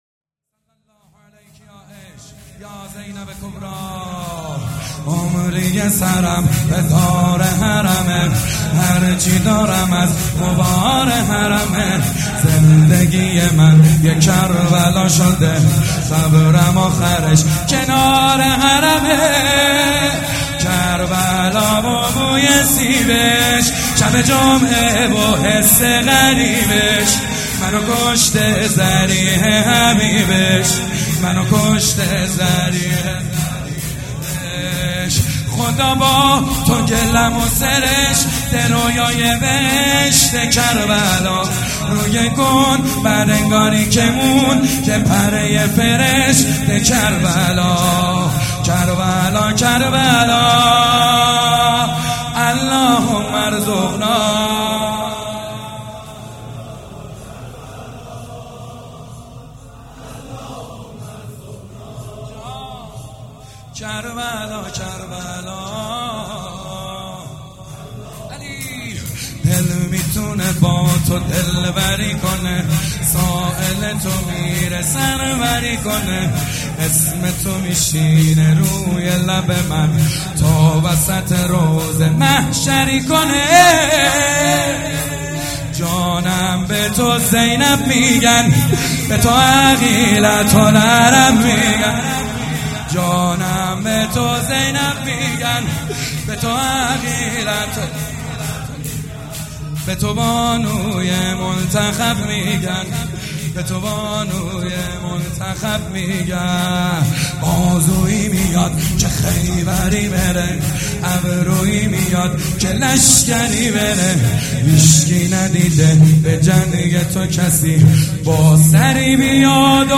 شور
مداح
کربلایی محمدحسین حدادیان
وفات حضرت زینب (س)